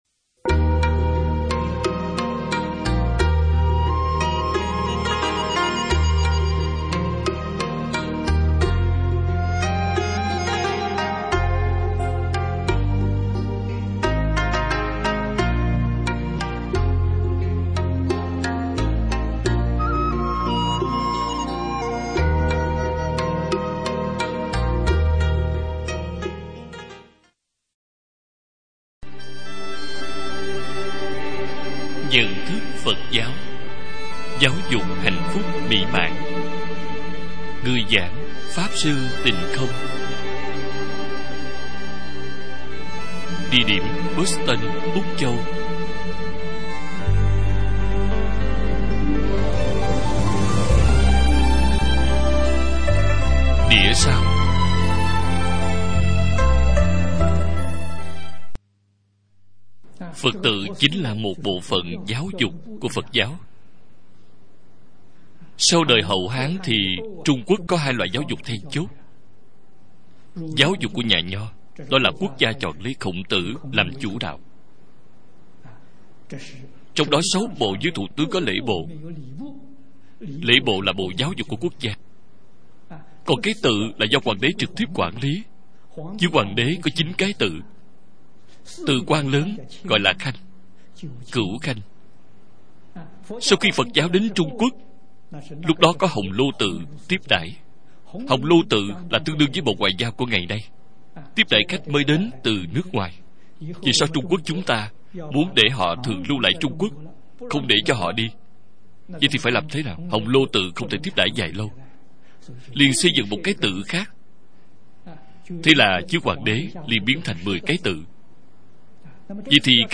Nhận Thức Phật Giáo - Bài giảng Video